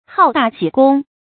注音：ㄏㄠˇ ㄉㄚˋ ㄒㄧˇ ㄍㄨㄙ
好大喜功的讀法